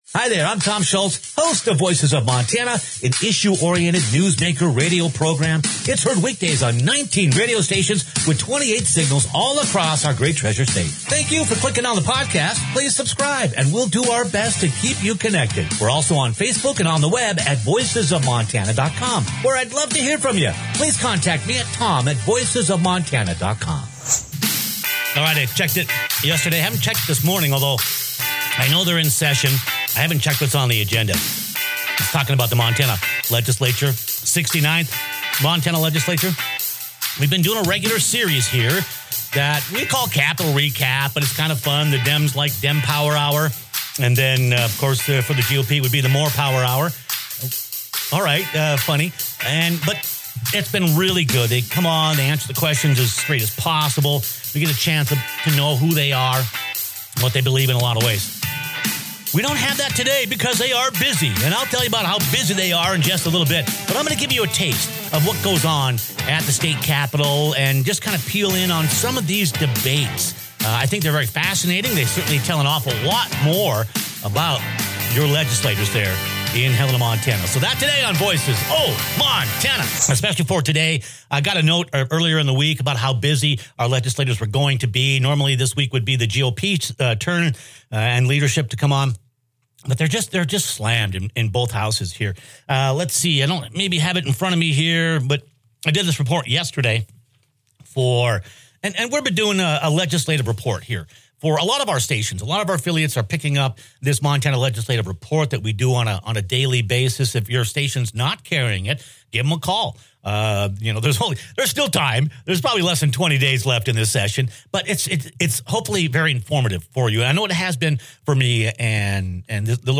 Here the compelling conversations from listeners who share the stories of tragedy and challenge that shape their views on physician-assisted suicide, as the Montana Legislature tackles the issue in the 69th session.